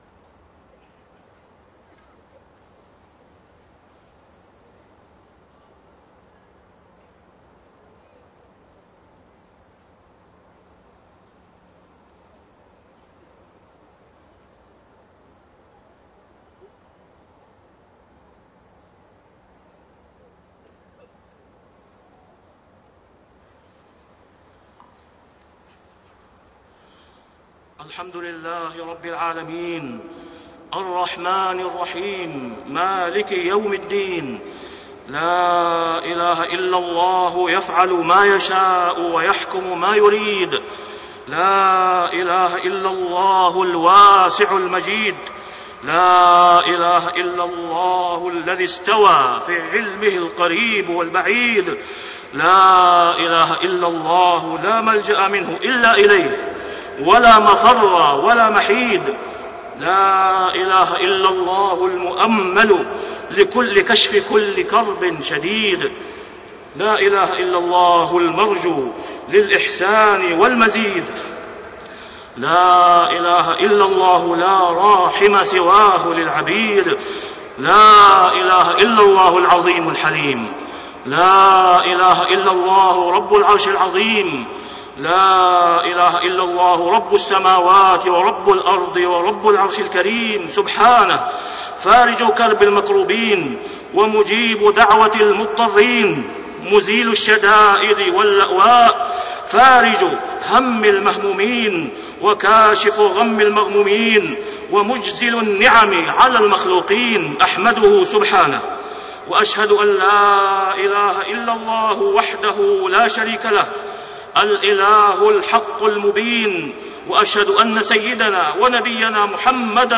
خطبة الاستسقاء 13 صفر 1432هـ > خطب الاستسقاء 🕋 > المزيد - تلاوات الحرمين